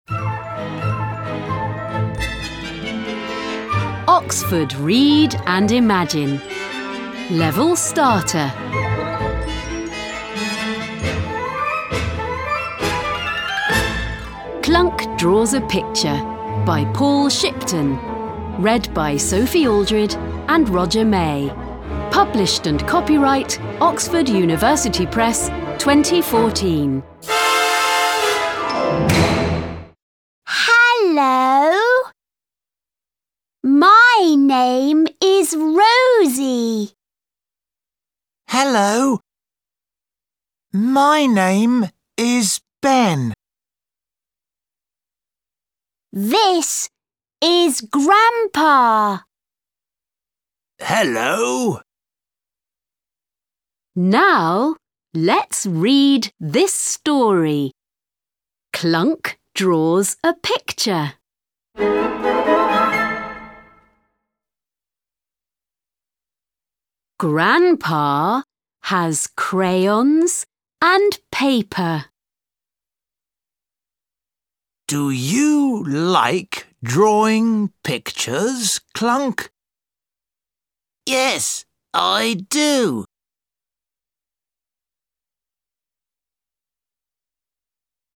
Track 1 Clunk Draws British.mp3